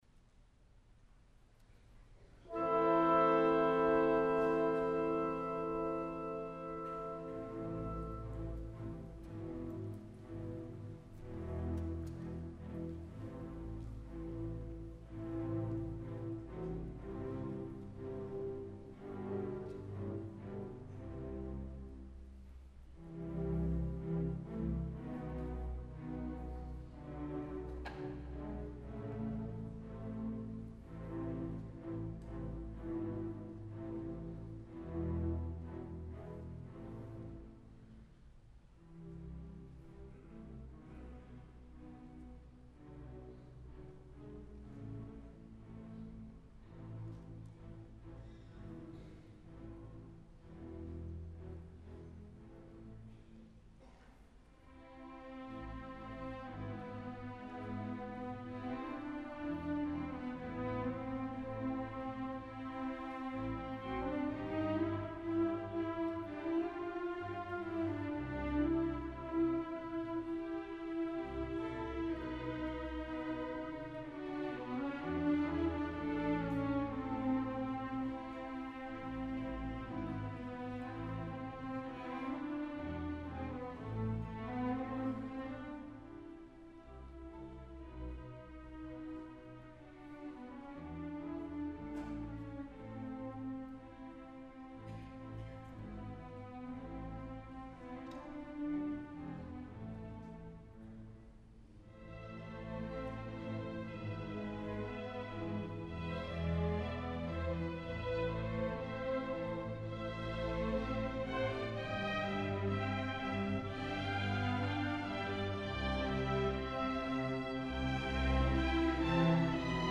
My favorite part of Beethoven's Symphony No. 7 is the second movement in A minor.
Allegretto Stuffed Animal Symphony Orchestra playing the Allegretto from Beethoven's Symphony No. 7